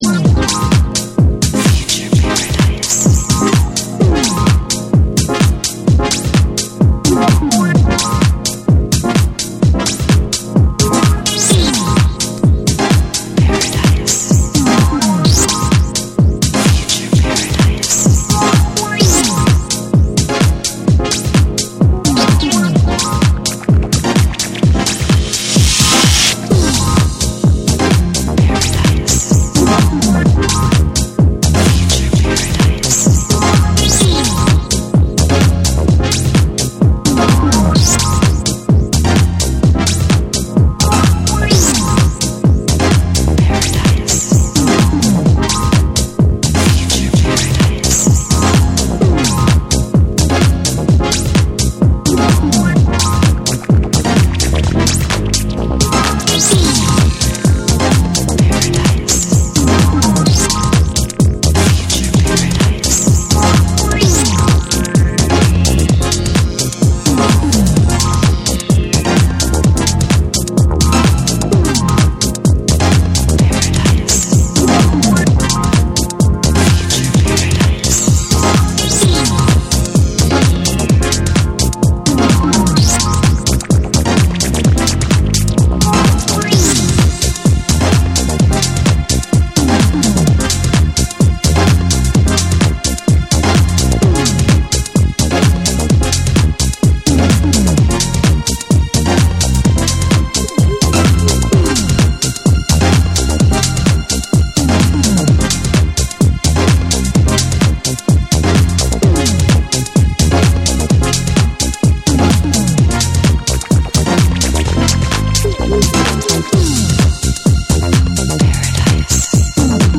多幸感あふれるシンセのメロディーとヴォーカル・サンプル、そして柔らかくうねるベースラインでフロアを包み込む
TECHNO & HOUSE